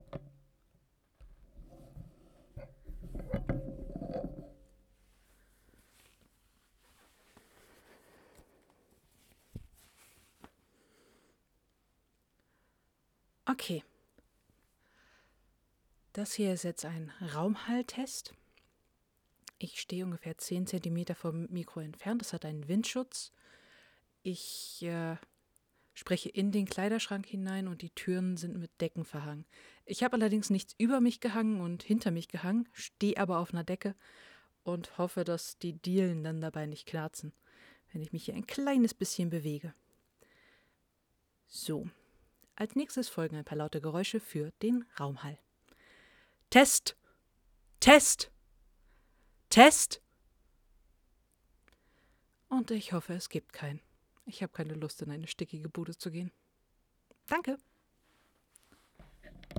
niedergeschlagen: ängstlich: spöttisch: hektisch: freudig:
Aufgenommen hab ich mit meinem Tascam DR05 wie folgt mit Windfellschutz ca. 17 cm Abstand Recording-lvl 75 auf den Lowcut habe ich verzichtet Kleiderschrankaufbau wie zuvor
Als ich's mir die Fouriertransformierte in Audition angesehen hab, wurden mir Frequenzen bis 20 kHz angezeigt.